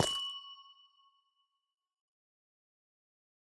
Minecraft Version Minecraft Version latest Latest Release | Latest Snapshot latest / assets / minecraft / sounds / block / amethyst / step10.ogg Compare With Compare With Latest Release | Latest Snapshot
step10.ogg